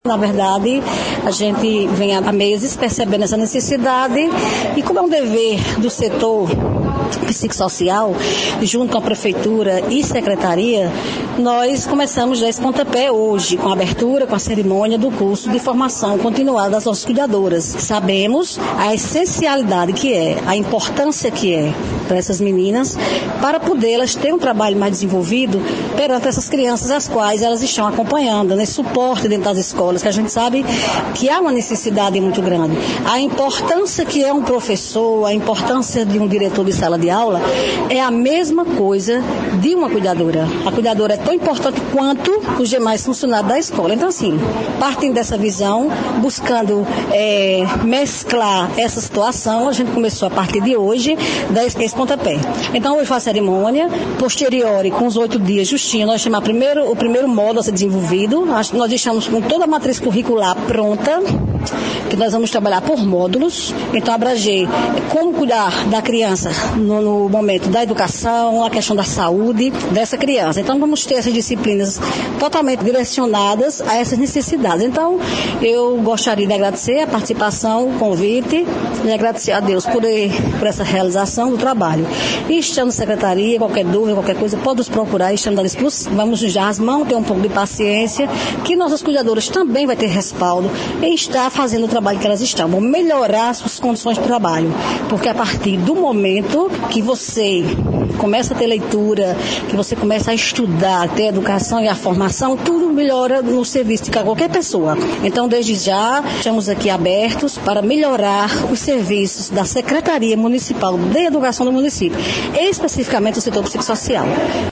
Fala da psicopedagoga